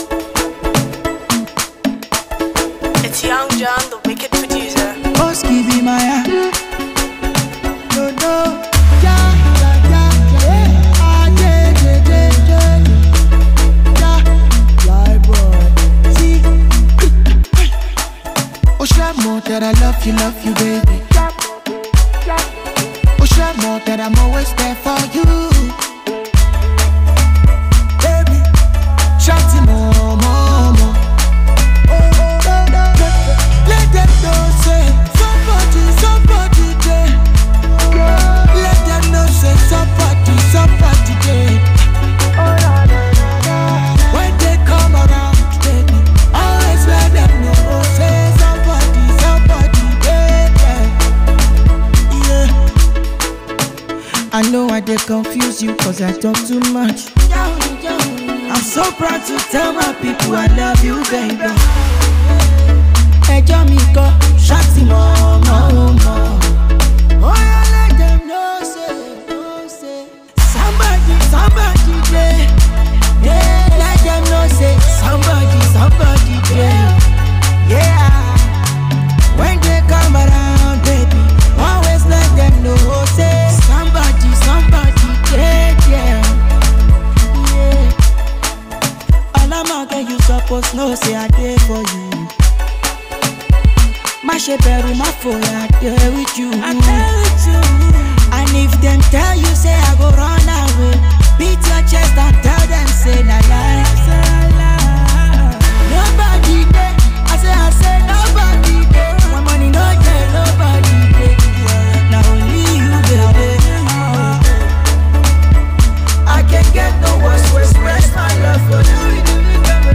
Here comes a valentine song for the love season
is a mid tempo tune made specially for the one in love